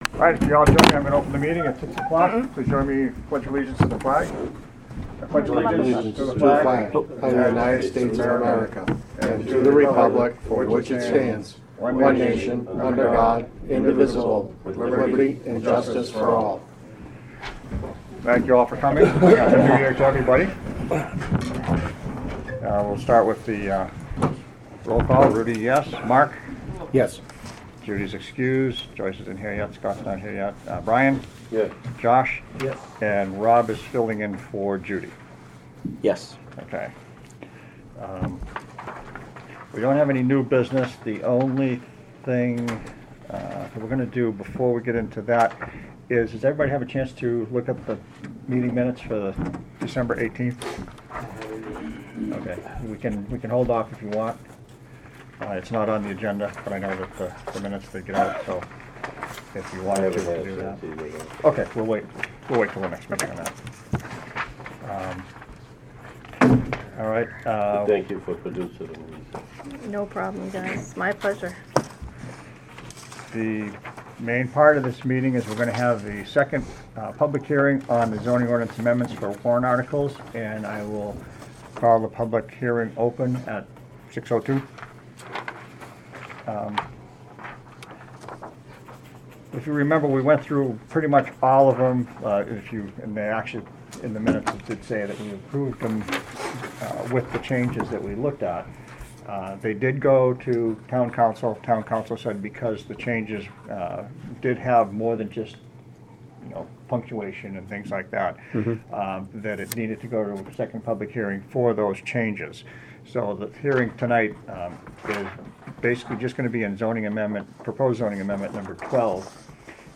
Audio recordings of committee and board meetings.
Planning Board Meeting